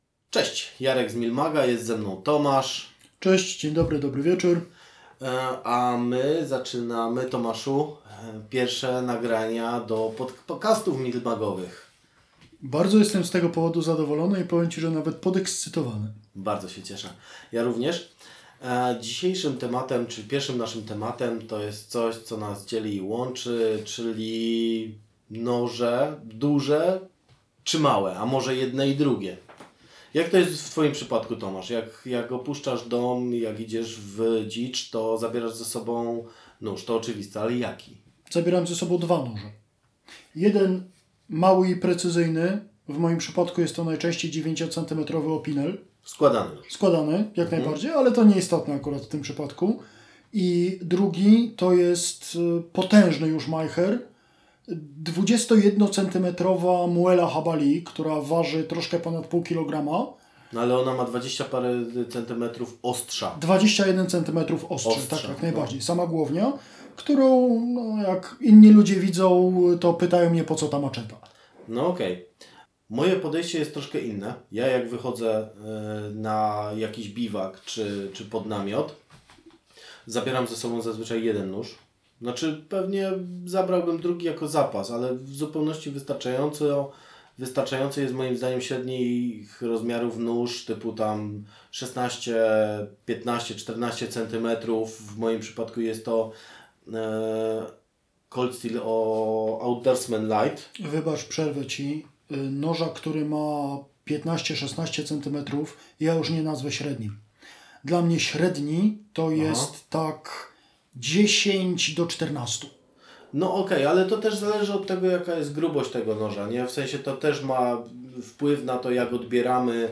Wokół tego zagadnienia toczy się rozmowa tego odcinka.